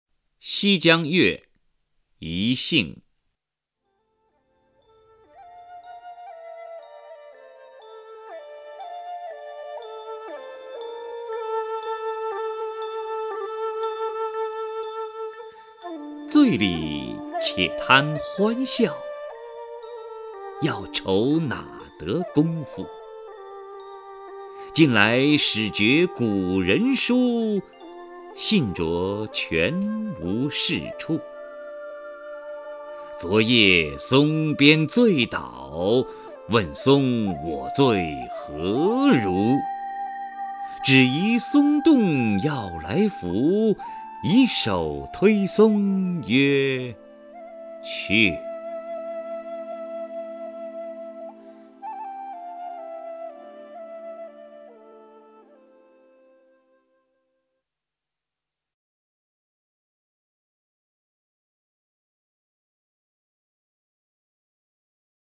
首页 视听 名家朗诵欣赏 王波
王波朗诵：《西江月·遣兴》(（南宋）辛弃疾)